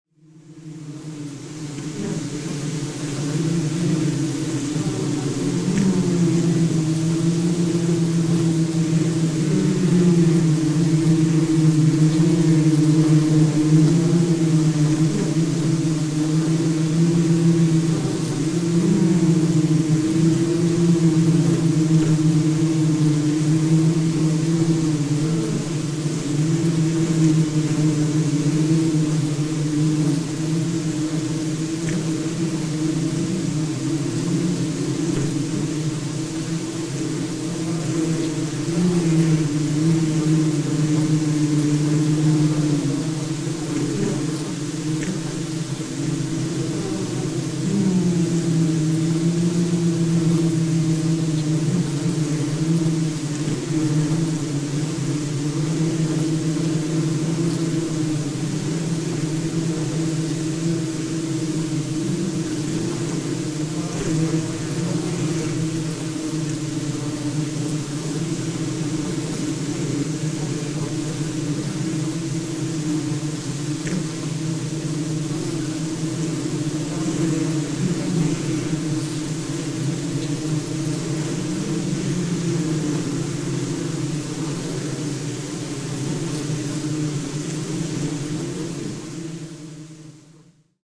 sound art ljudkonst